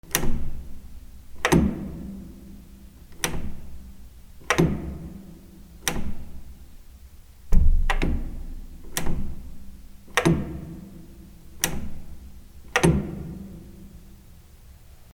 / K｜フォーリー(開閉) / K05 ｜ドア(扉)
マンションエントランス
『チャコン』